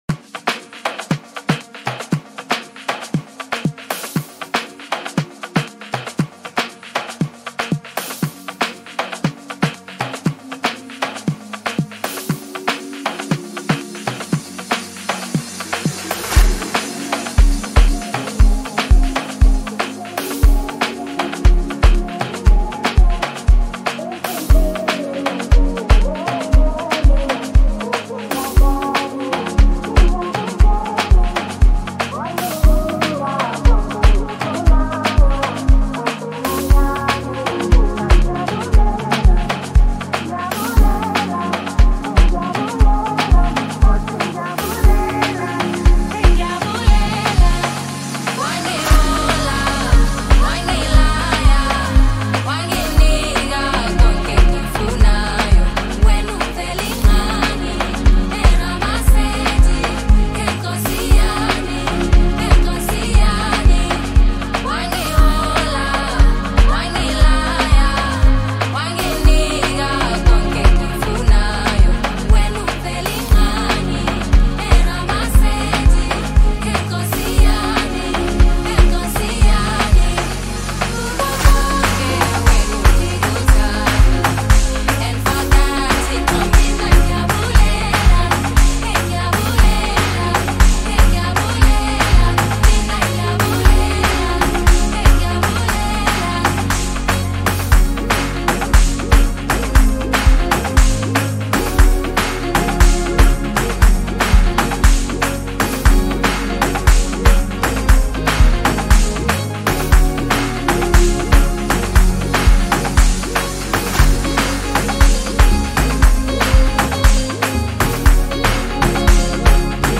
Home » Amapiano » DJ Mix » Hip Hop
South African singer-songsmith